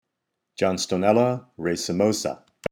Pronunciation/Pronunciación: John-sto-nél-la ra-ce-mò-sa Etymology/Etimología: "with flowers in racemes" Synonyms/Sinónimos: Basionym: Eritrichium racemosum S.Watson ex A.Gray, Proc.